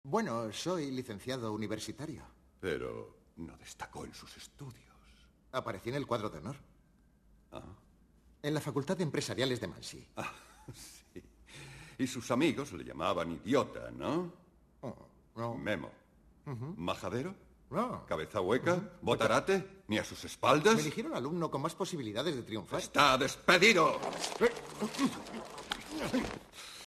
La pista doblada en castellano está en Dolby Digital 2.0 estéreo, a 192 Kbps.
Lamentablemente, no se ha aprovechado el nuevo doblaje para hacer una pista multicanal, sino que nos quedamos con un pobre sonido estereofónico.
Castellano redoblado.